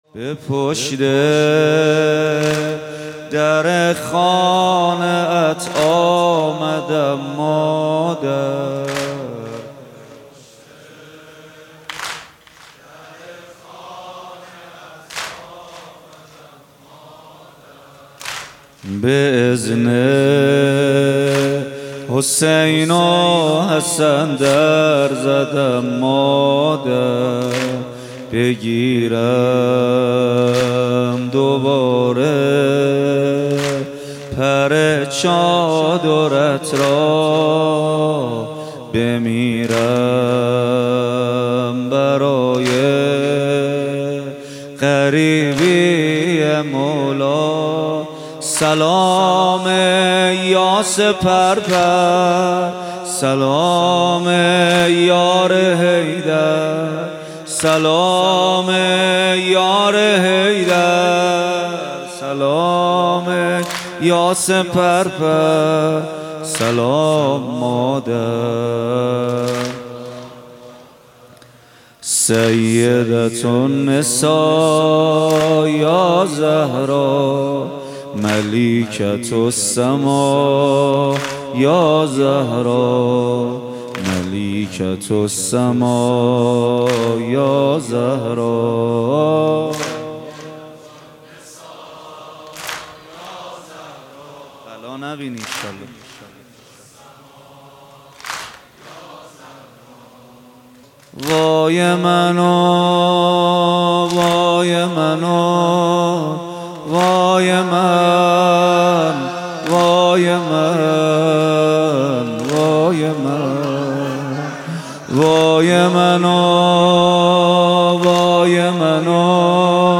مداحی
ایام فاطمیه 1442 | هیئت ریحانه النبی تهران